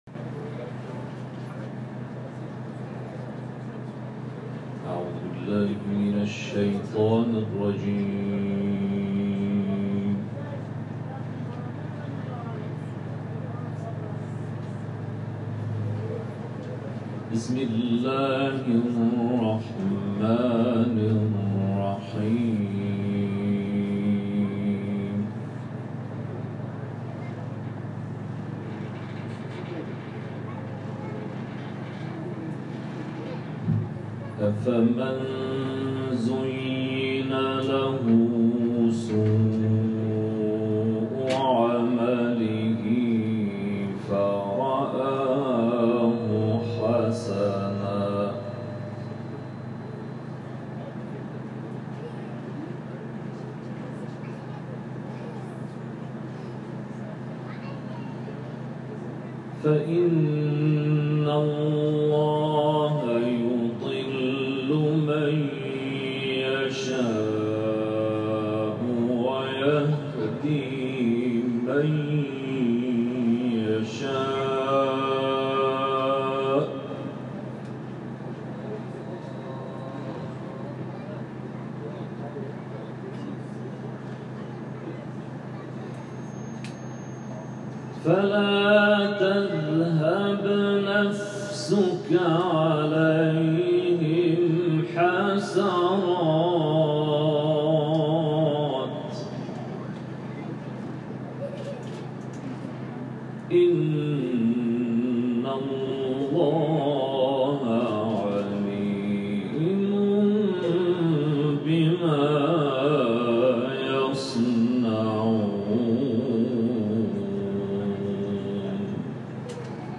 تلاوت در مسجد زینب س